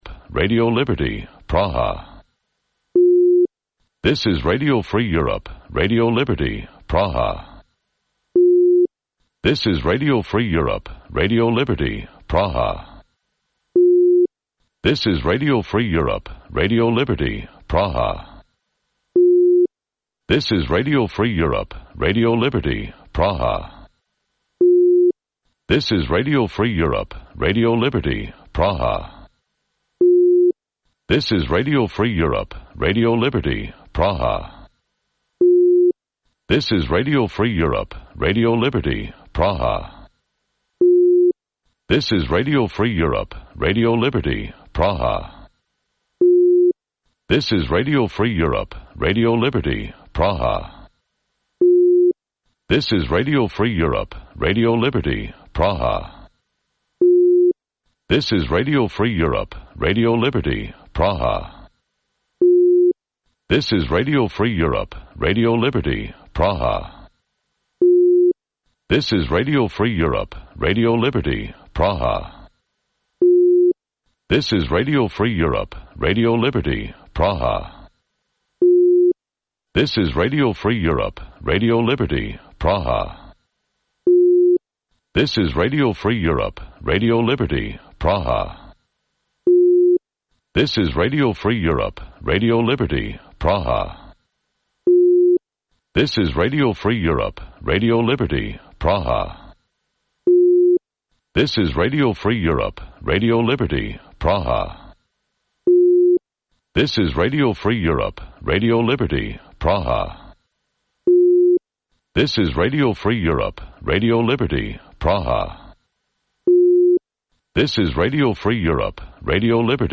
Вечірній ефір новин про події в Криму. Усе найважливіше, що сталося станом на цю годину.